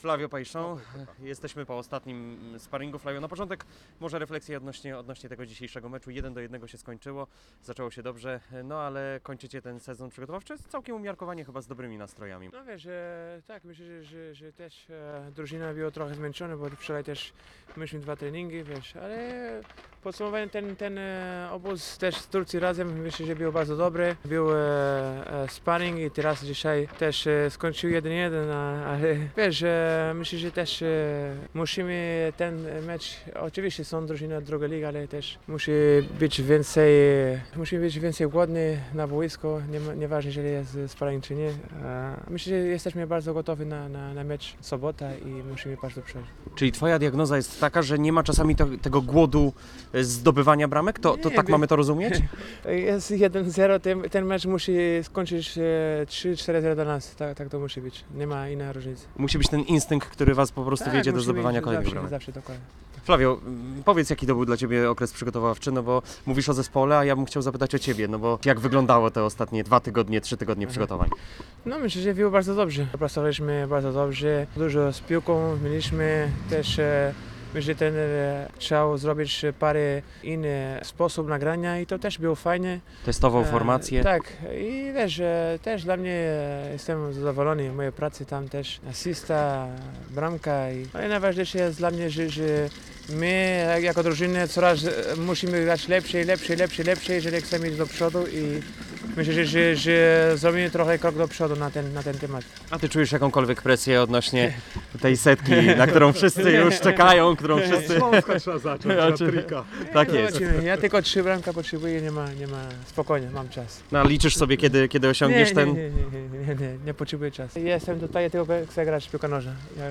Cała rozmowa z Flavio Paixao jest poniżej: